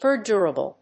音節per・dur・a・ble 発音記号・読み方
/(ː)d(j)ˈʊ(ə)rəbl(米国英語), (ː)djˈʊər(ə)rəbl(英国英語)/